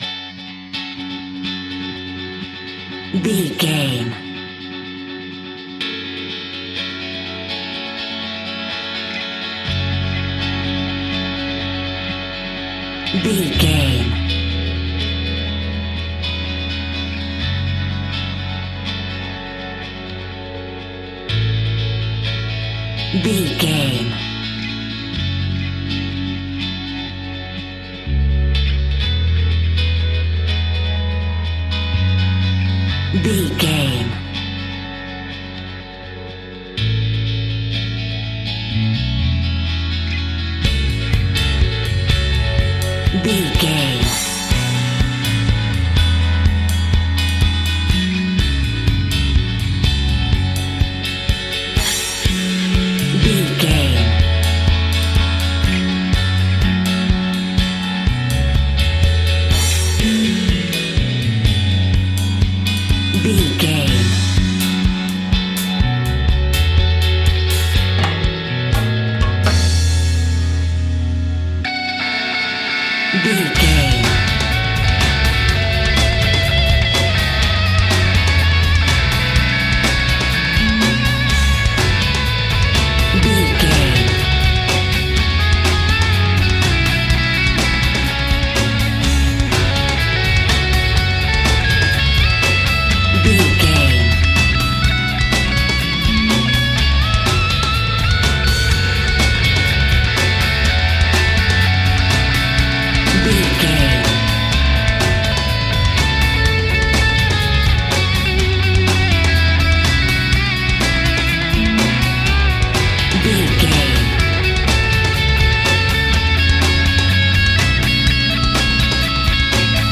Aeolian/Minor
D♭
uplifting
bass guitar
electric guitar
drums
cheerful/happy